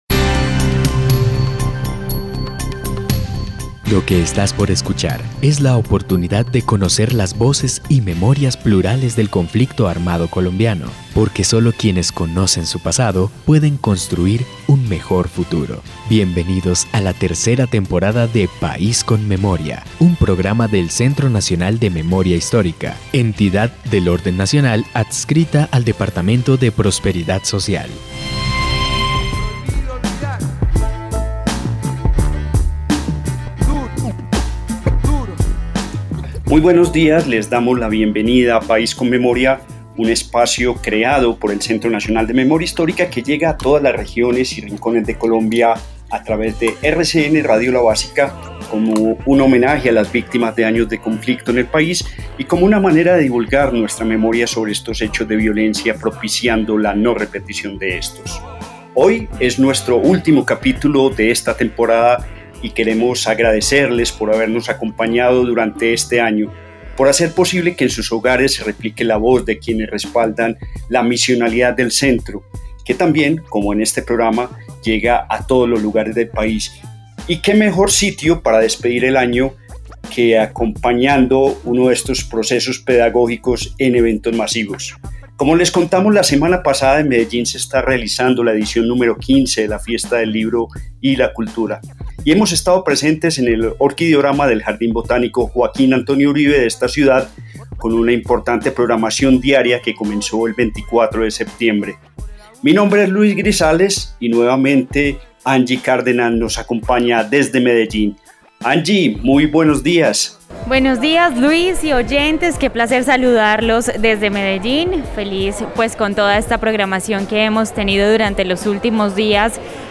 locución.
Descripción (dcterms:description) Capítulo número 25 de la tercera temporada de la serie radial "País con Memoria".